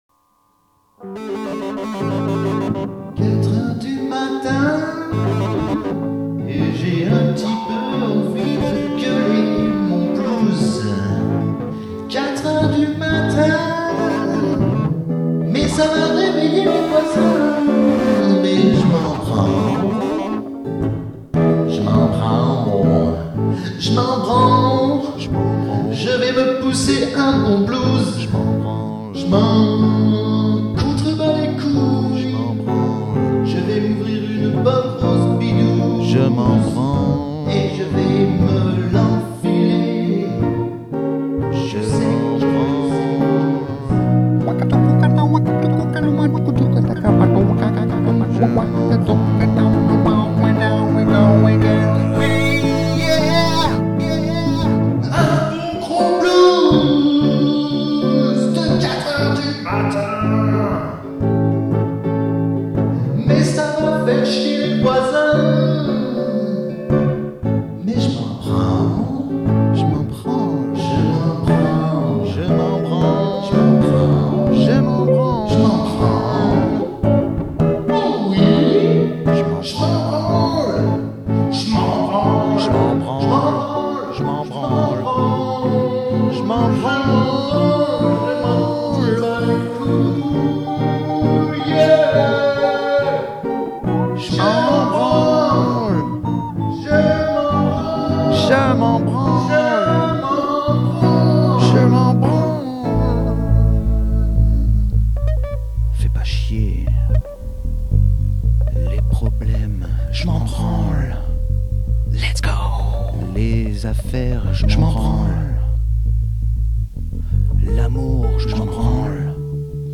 Blues de 4h du matin. 2005